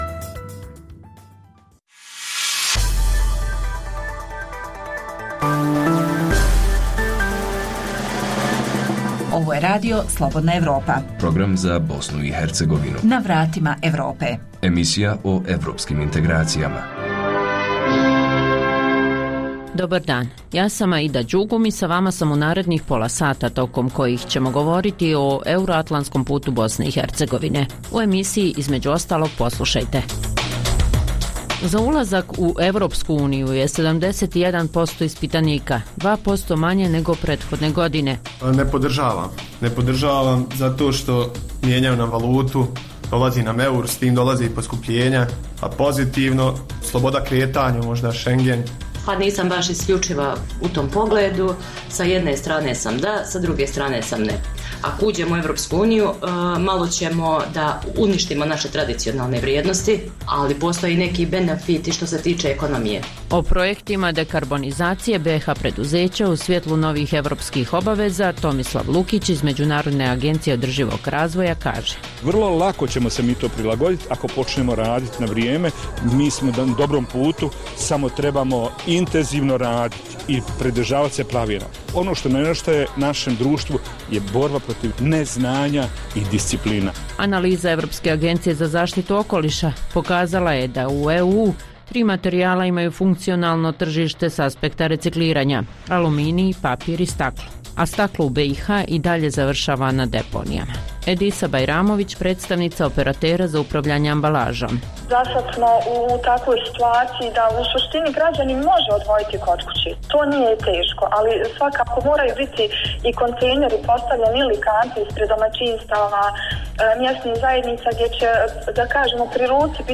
Emisija o putu BiH ka Evropskoj uniji i NATO sadrži vijesti, analize, reportaže i druge sadržaje o procesu integracije.